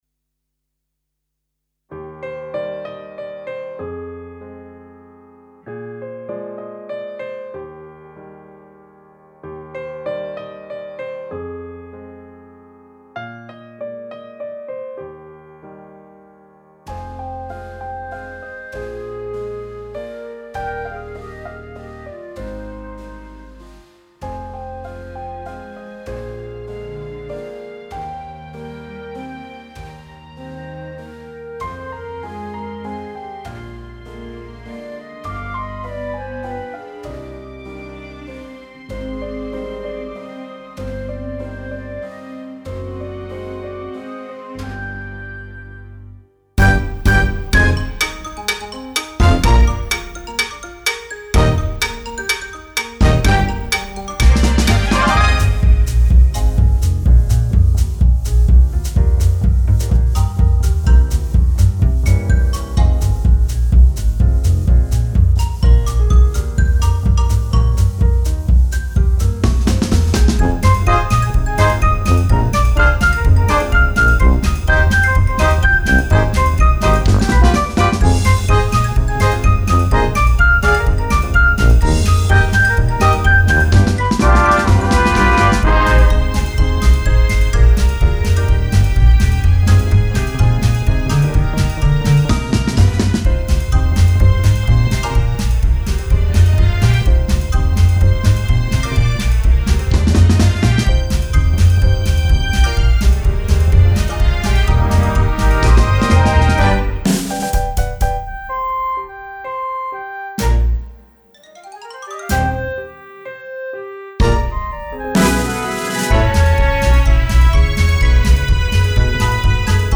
BACKING COMPLETO SIN ACABAR.mp3